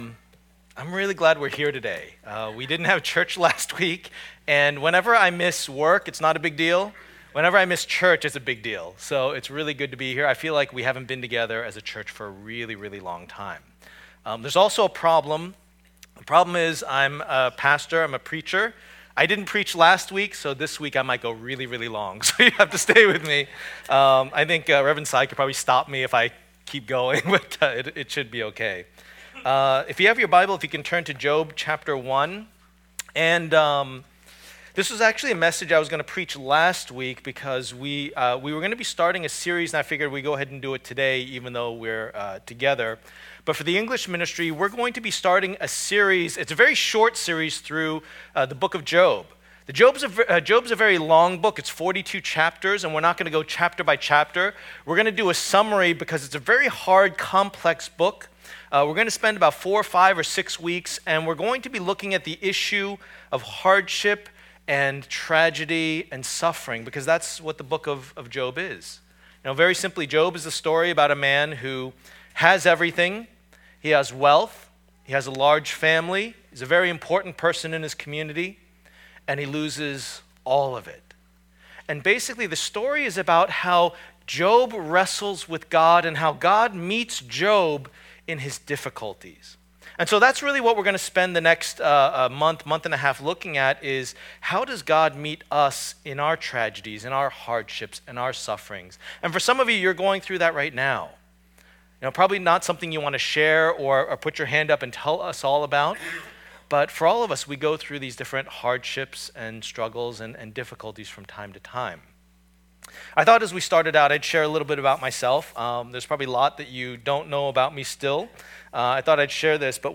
Passage: Job 1:1-12 Service Type: Lord's Day